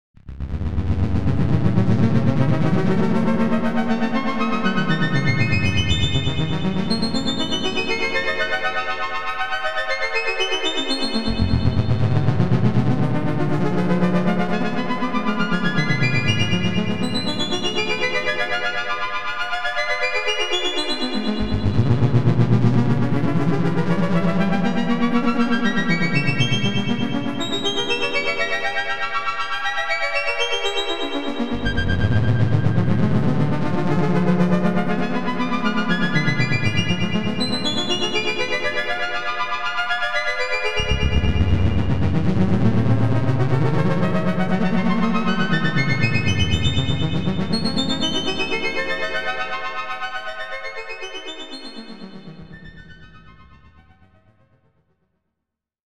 Eerie Mystical Magic Sound Effect
Add suspense to your project with an eerie dark magic sound effect. Perfect for horror videos, games, and haunted scenes, it creates a chilling and mysterious atmosphere. Scary sounds.
Eerie-mystical-magic-sound-effect.mp3